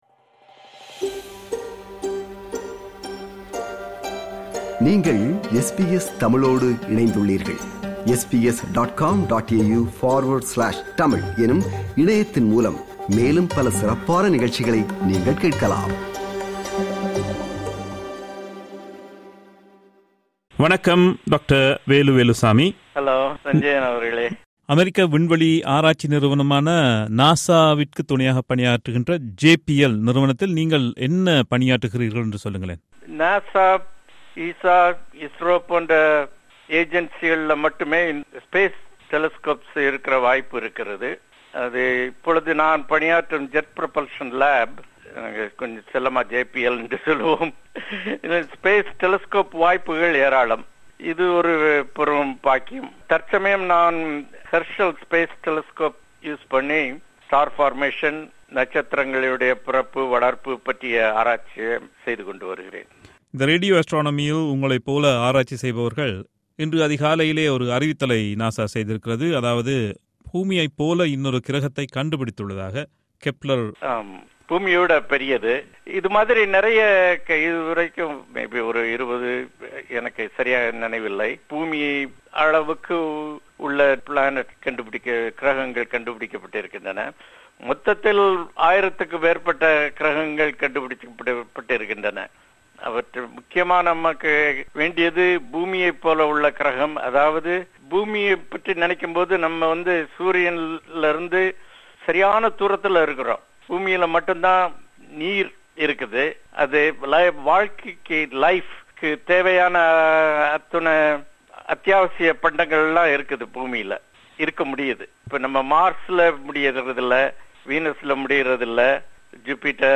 This interview was first broadcast in July 2015.